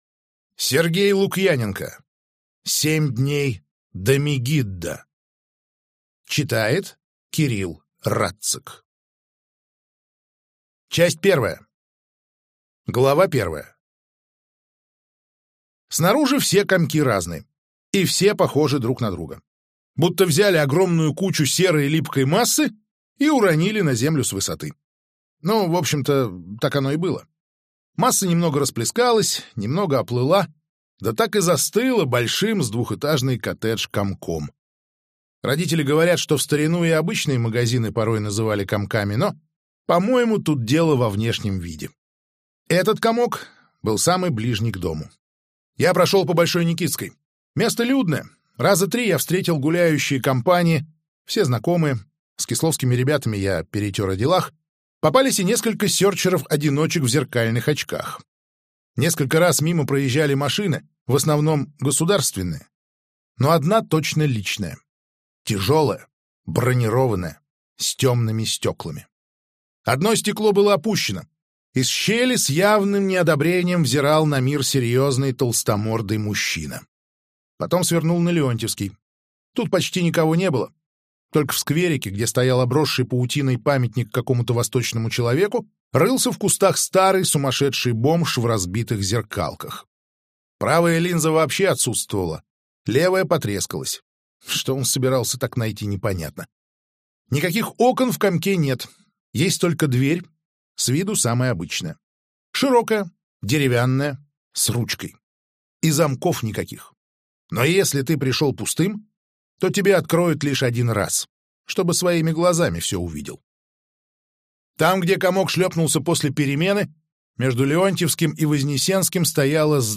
Аудиокнига Семь дней до Мегиддо - купить, скачать и слушать онлайн | КнигоПоиск